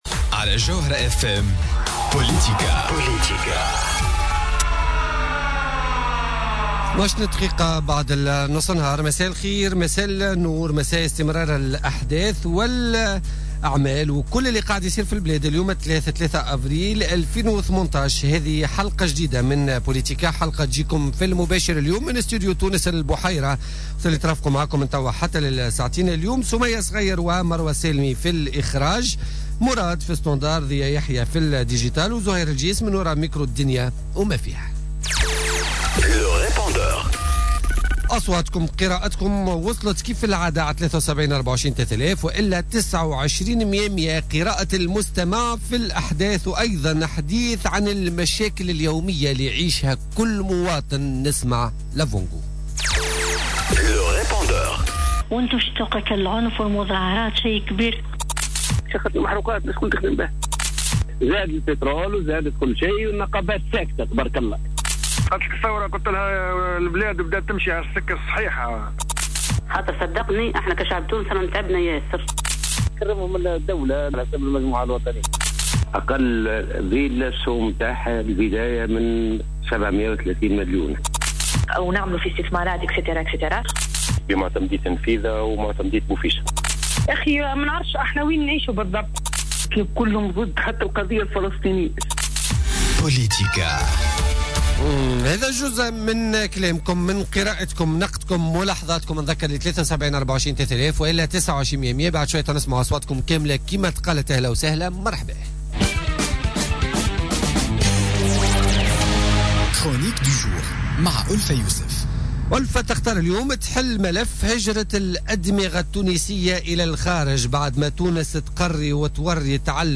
منذر بالحاج علي نائب بمجلس نواب الشعب ضيف بوليتيكا